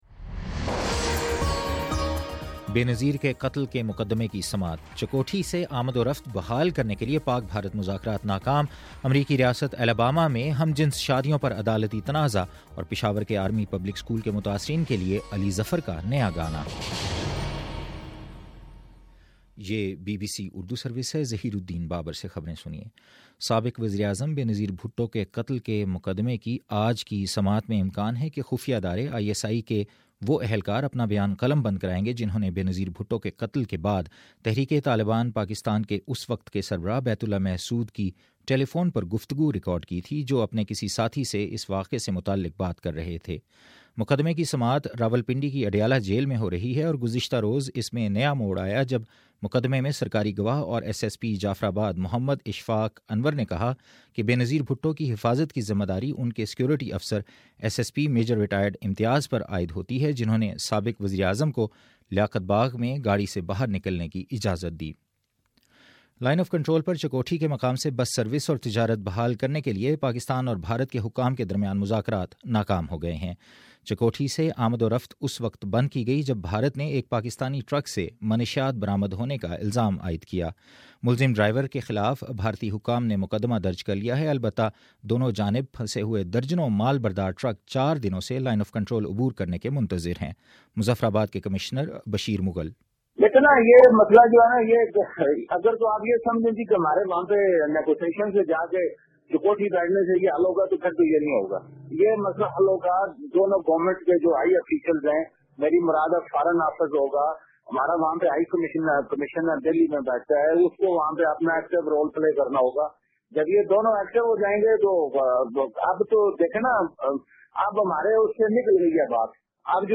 فروری10: صبح نو بجے کا نیوز بُلیٹن